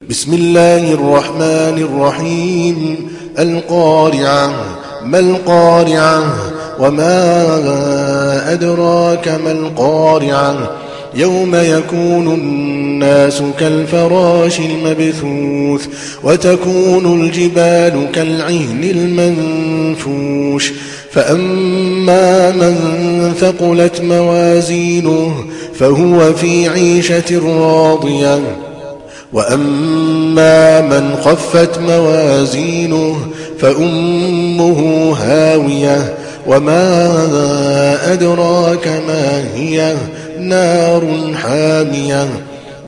تحميل سورة القارعة mp3 بصوت عادل الكلباني برواية حفص عن عاصم, تحميل استماع القرآن الكريم على الجوال mp3 كاملا بروابط مباشرة وسريعة